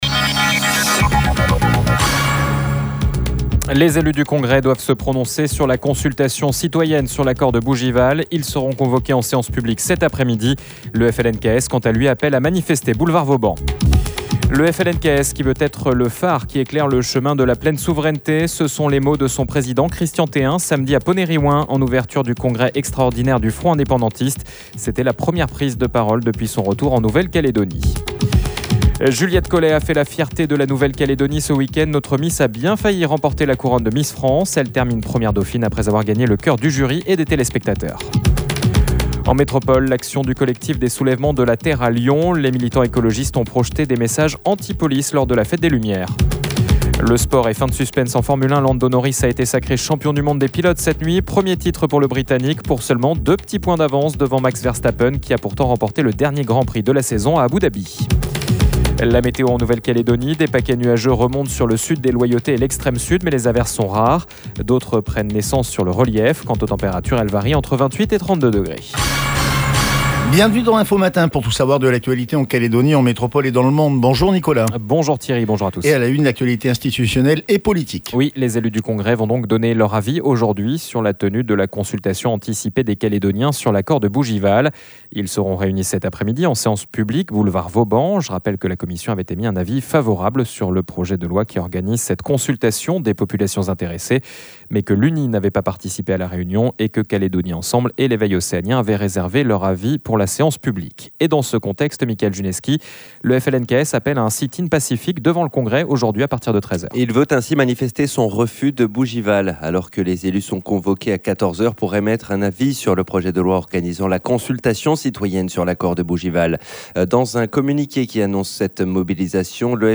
Le Journal